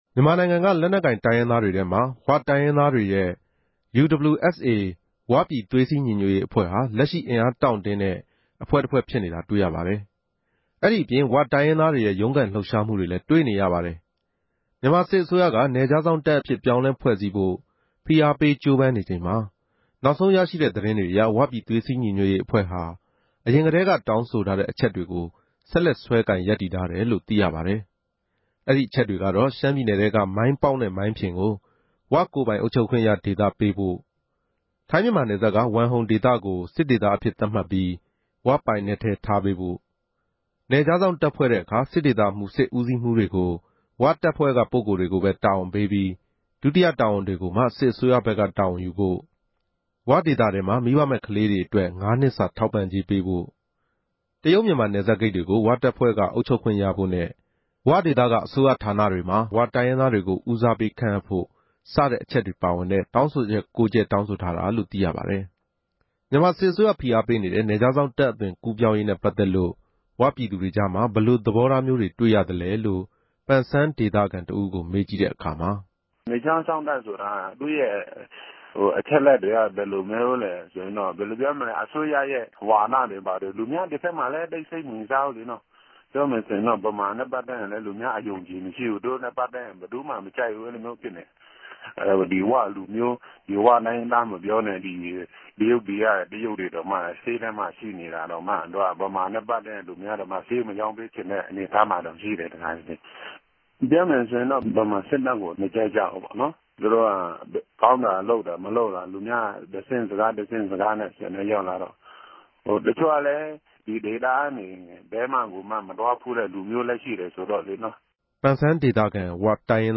ဒၝနဲႛ ပတ်သက်္ဘပီး ပန်ဆန်းဒေသမြာ နေထိုင်တဲ့ ဝတိုင်းရင်းသားတဦးက အခုလို ေူပာပၝတယ်။
စုစည်းတင်ပြခဵက်။